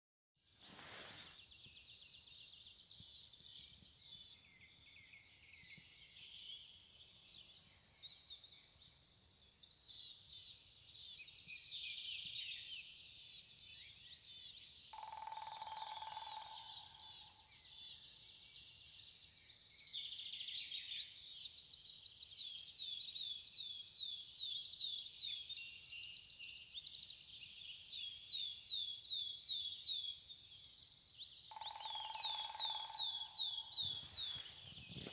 Grey-headed Woodpecker, Picus canus
Administratīvā teritorijaVentspils novads
StatusSinging male in breeding season